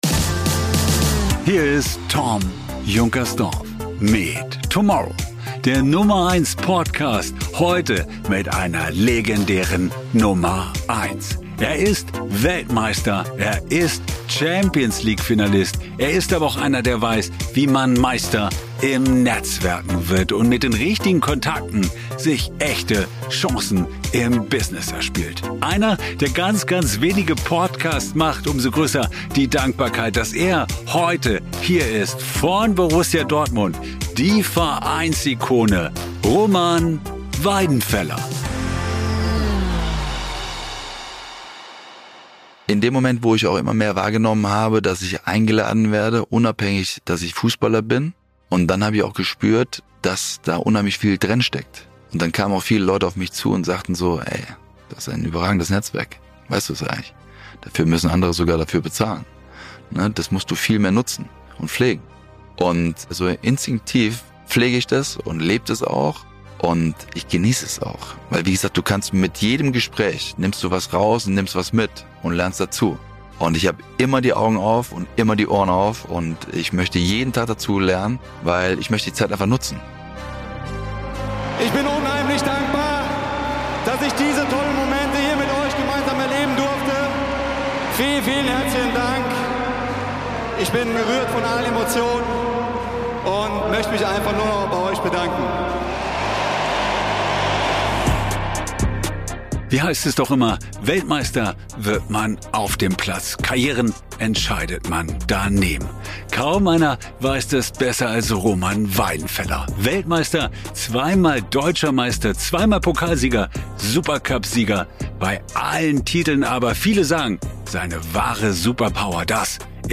Wir treffen uns im Roomers Hotel in München und sprechen offen über: - Wie aus Kontakten echte Karriere-Chancen entstehen.